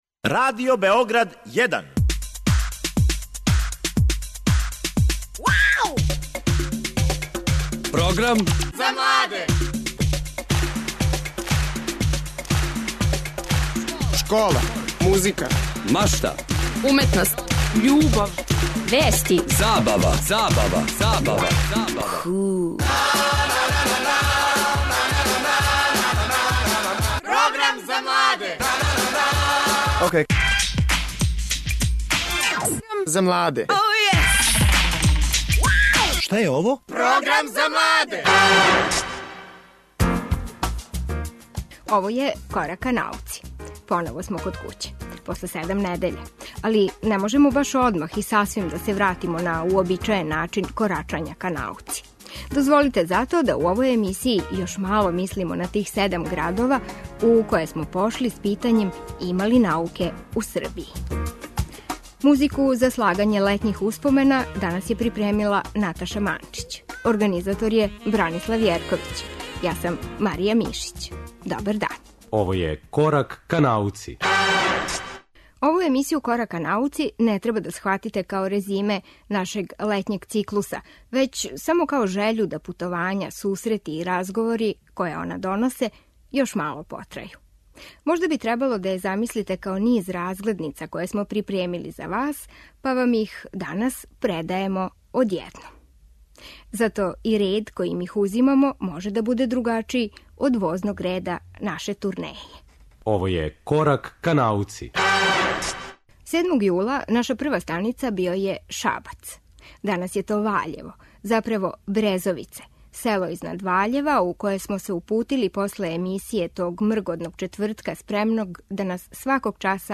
Корак ка науци састављен је од прилога снимљениих на турнеји 'Има ли науке у Србији' реализованој у седам градова током протеклих седам четвртака.
Из Ваљева - прилог снимљен међу подмлатком истраживачког друштва "Владимир Мандић-Манда" који су у селу Брезовице у летњој еколошкој школи.